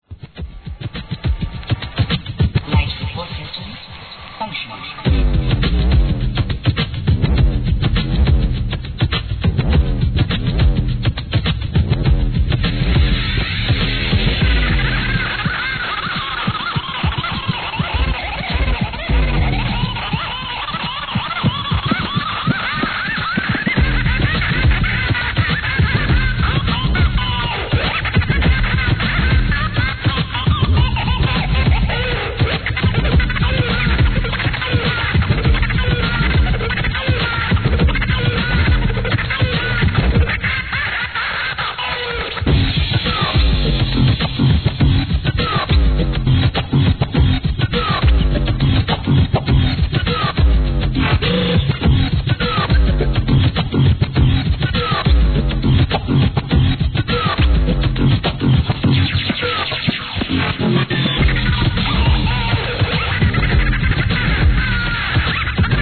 HIP HOP/R&B
ブレイク・ビーツ